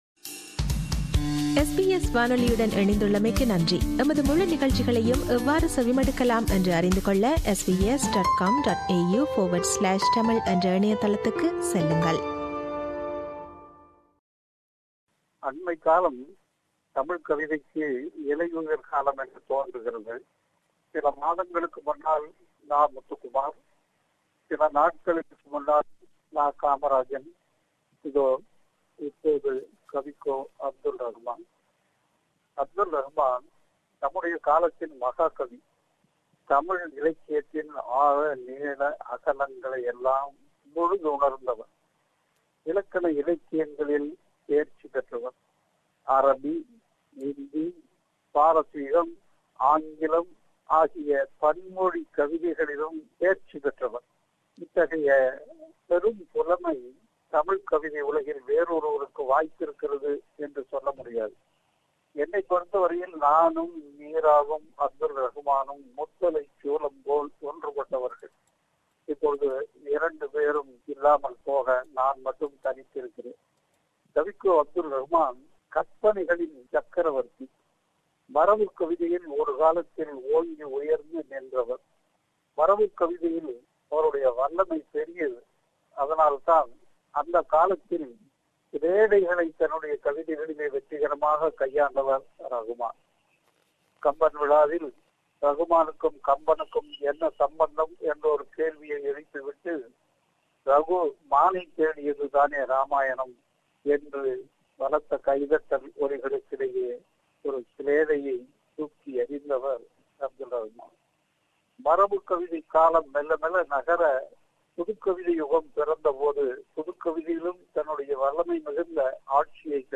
Tamil poet Kaviko S. Abdul Rahman passed away today after a brief illness. Sirpi Balasubramaniam, a Tamil poet, critic, scholar and professor presents a eulogy to Kavikko.